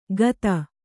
♪ gata